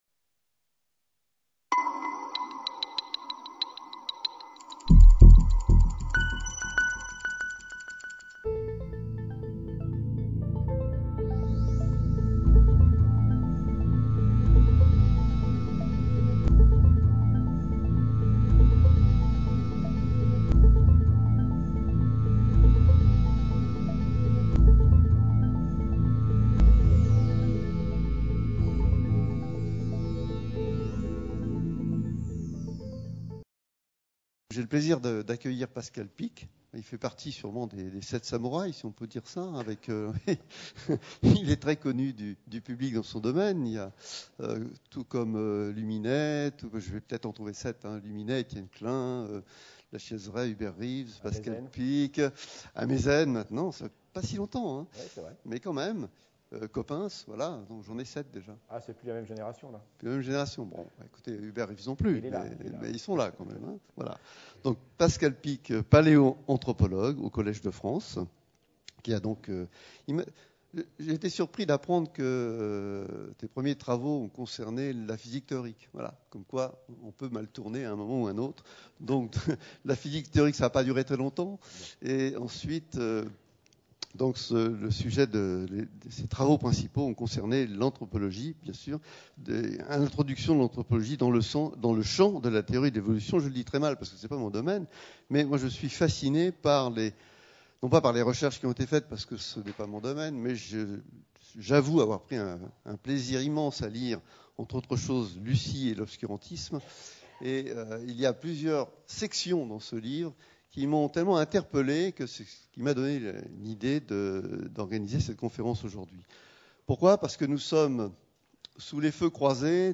Conférence de l'Institut d'Astrophysique de Paris présentée par Pascal Picq (paléoanthropologue, maître de conférence au Collège de France) le 5 mars 2013. Le jeune Charles Darwin rencontre le célèbre astronome John Herschel au Cap, en Afrique du Sud, le 3 juin 1836 alors que son long voyage d'exploration sur le HMS Beagle arrive à son terme.